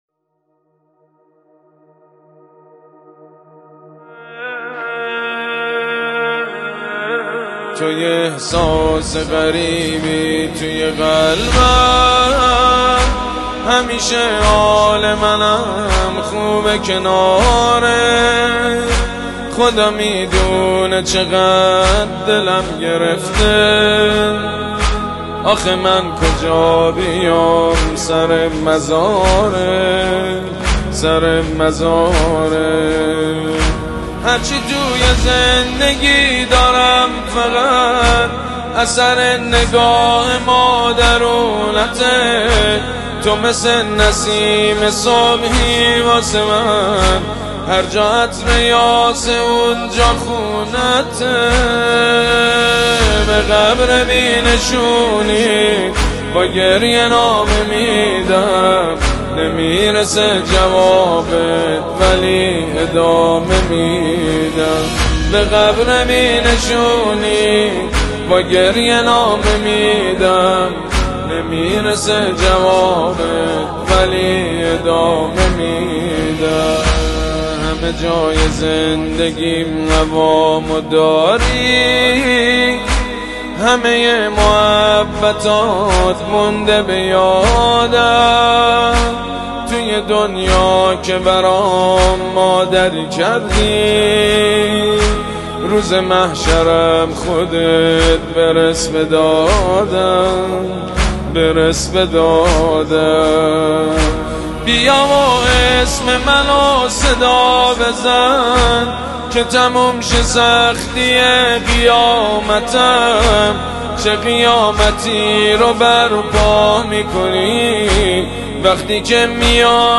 متن مداحی